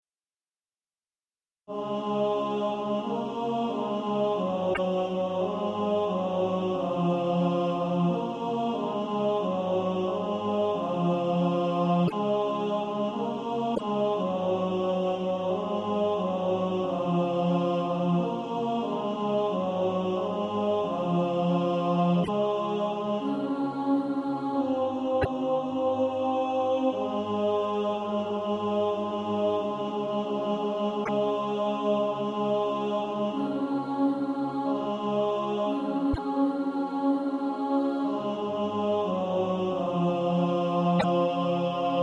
Tenor Track.
Practice then with the Chord quietly in the background.